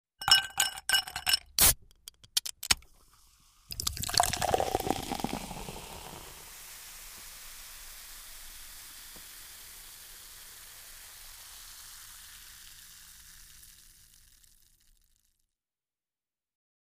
Звуки ресторана
Бросают лед в стакан, открывают банку газировки, наливают